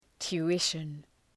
Προφορά
{tu:’ıʃən}